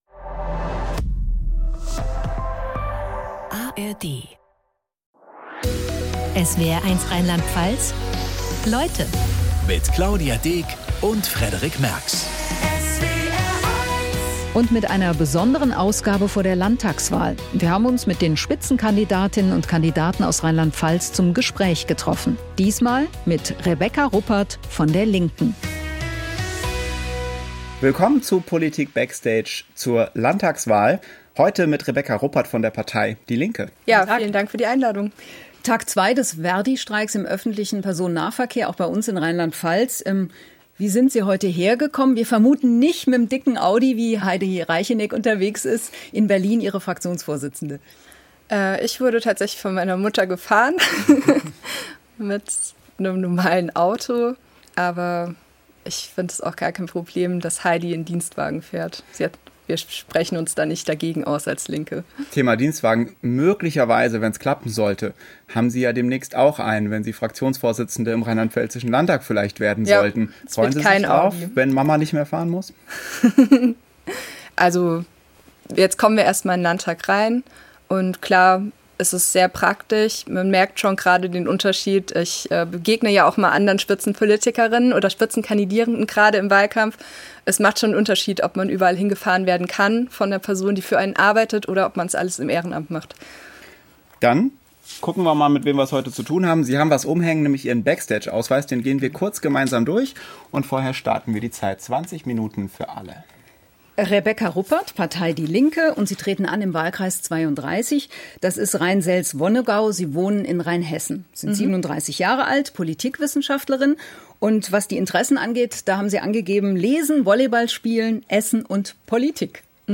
Am 28. Februar 2026 war Rebecca Ruppert von der Linken zu Gast im SWR. Aus intensiven Gesprächen über den Wahlkampf, die Parteiprogramme und nicht zuletzt über den Menschen "hinter" der politischen Rolle wird so POLITIK BACKSTAGE.